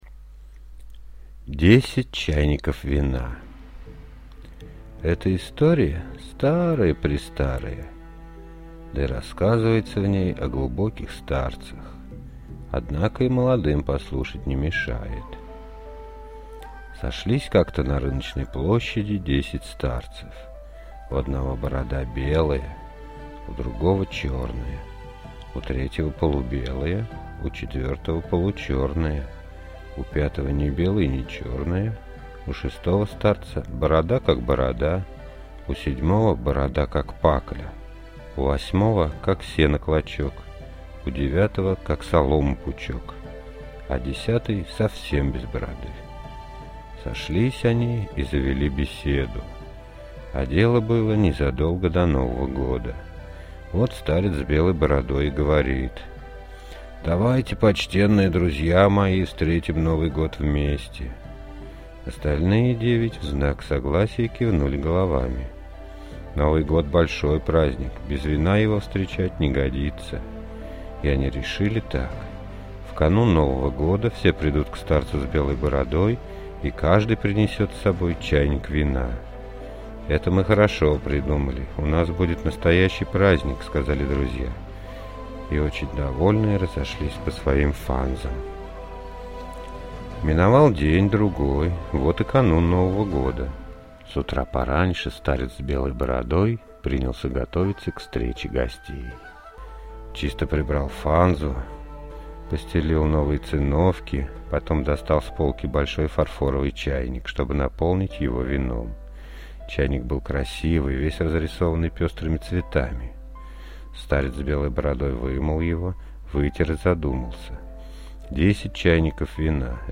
Десять чайников вина – китайская аудиосказка